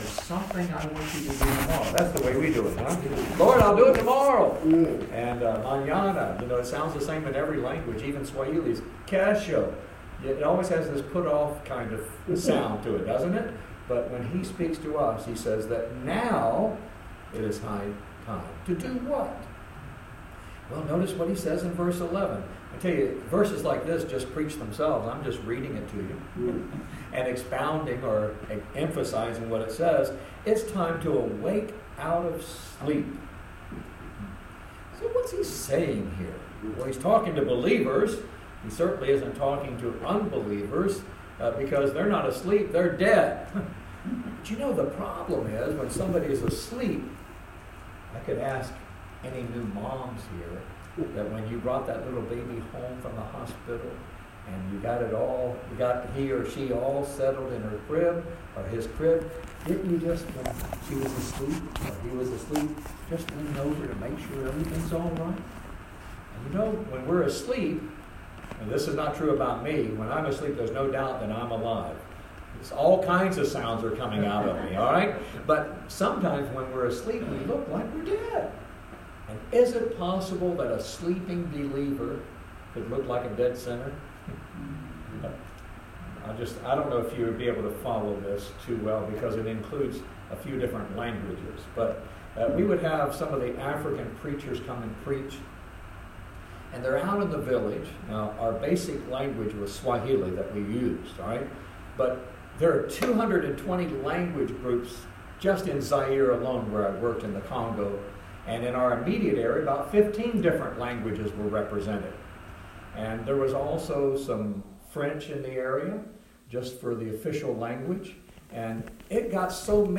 Passage: Romans 13:11-14 Service Type: Wednesday Night Bible Text